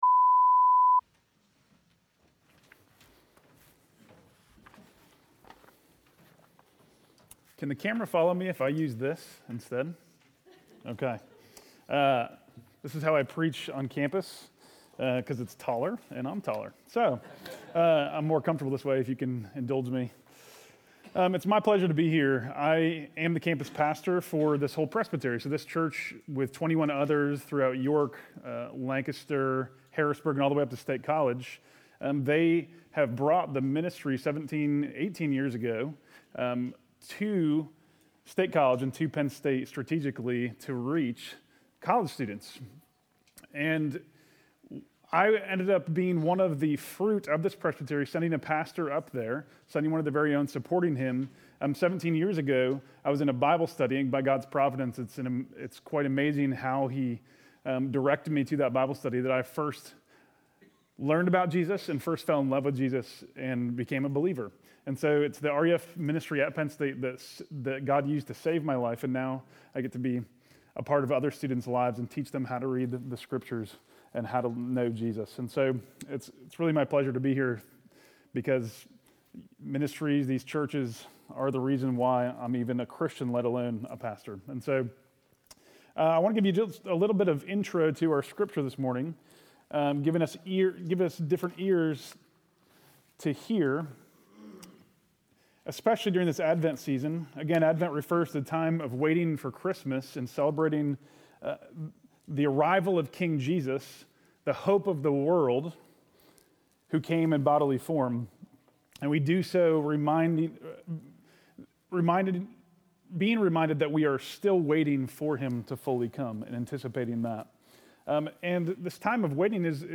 Sunday Worship – November 28, 2021 – The Mission of God